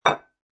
descargar sonido mp3 mostrador 1